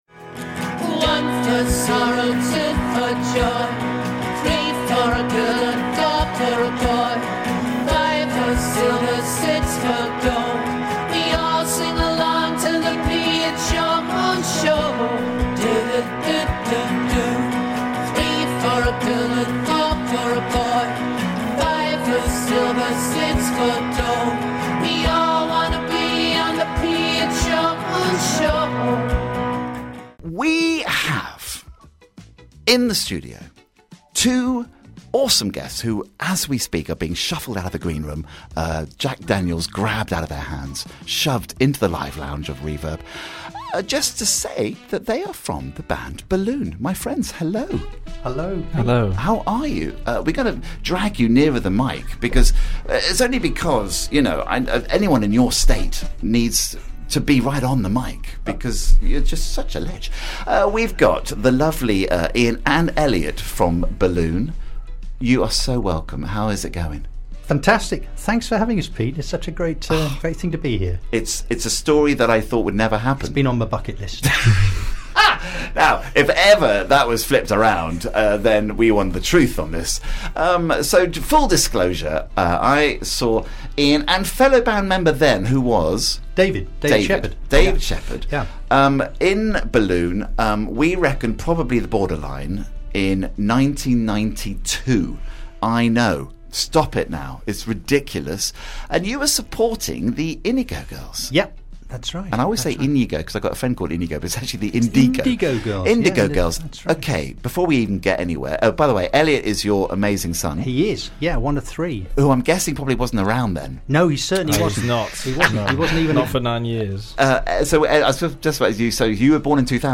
A one off one hour live session (unedited)